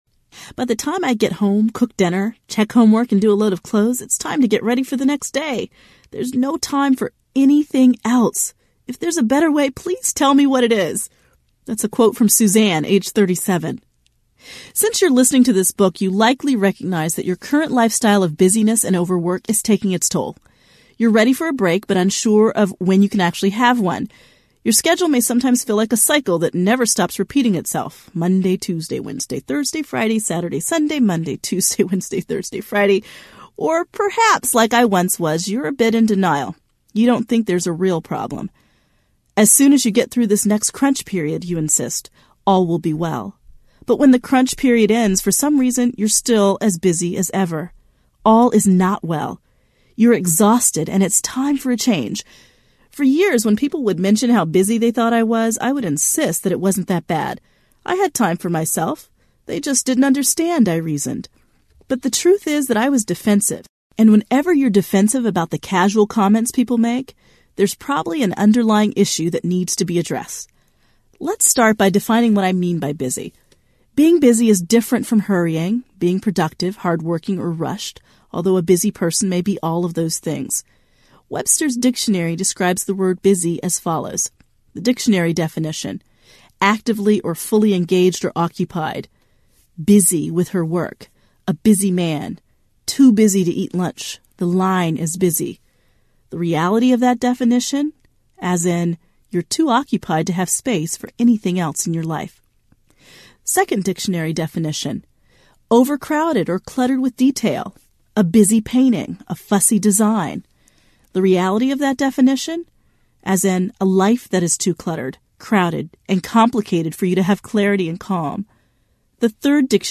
How Did I Get So Busy? Audiobook
5.8 Hrs. – Unabridged